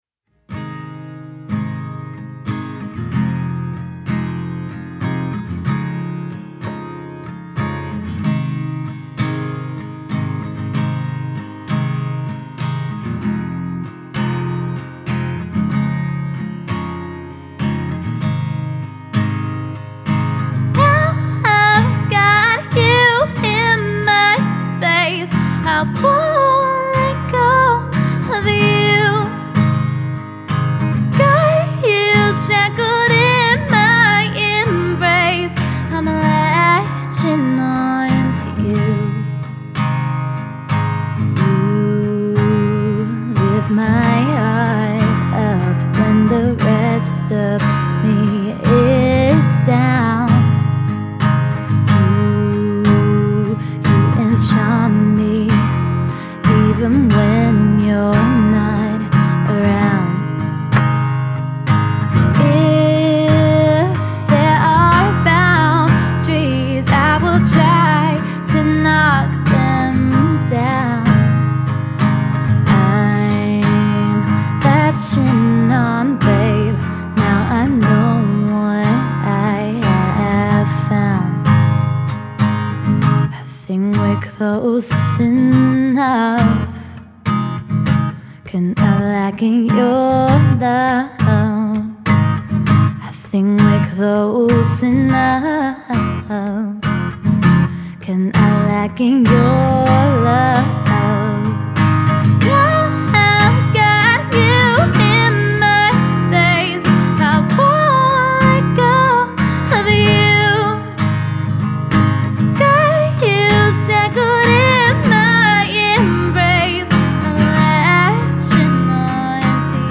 singer guitarists